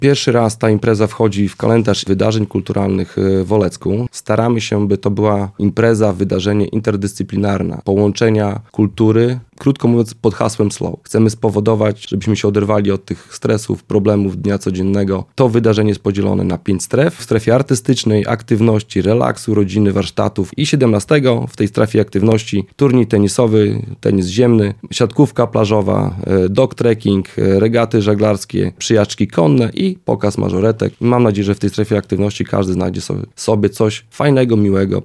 Mówi Karol Sobczak, burmistrz Olecka.